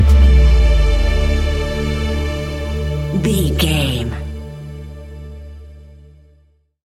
Ionian/Major
D♭
electronic
techno
trance
synths
synthwave